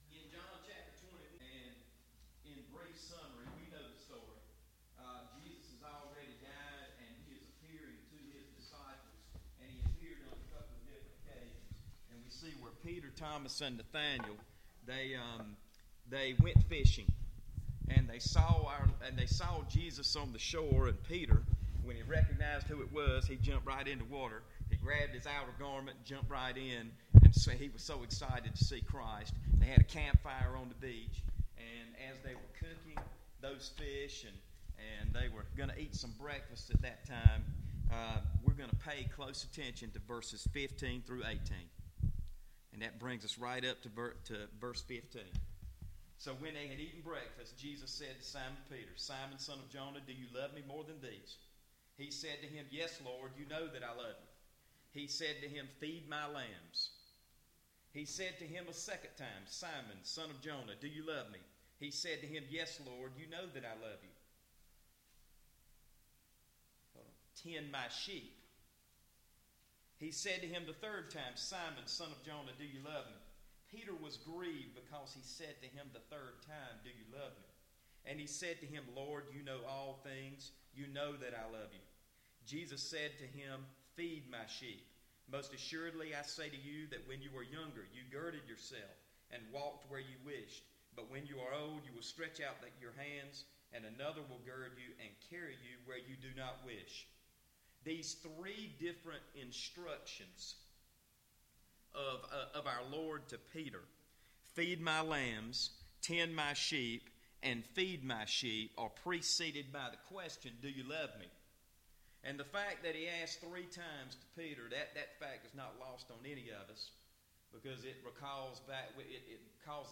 Service Type: VBS Adult Class